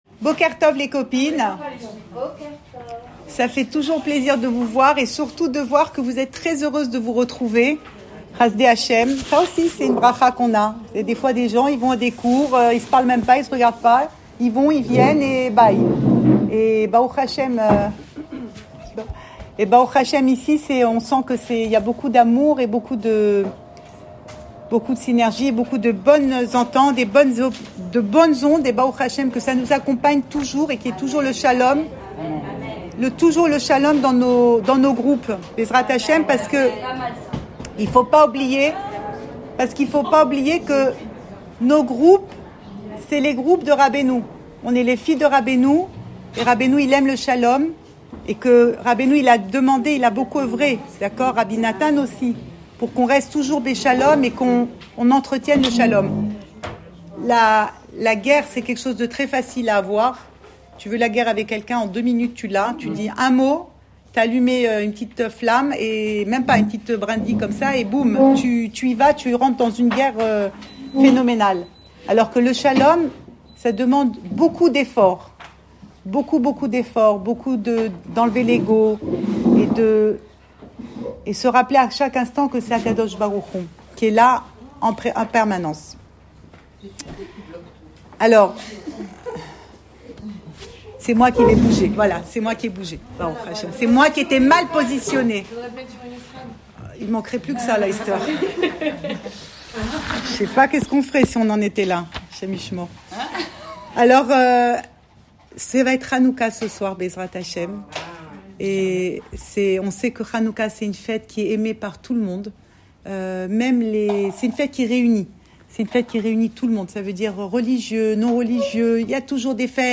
Cours audio Fêtes Le coin des femmes Le fil de l'info Pensée Breslev - 25 décembre 2024 25 décembre 2024 Hannouka, no limit. Enregistré à Tel Aviv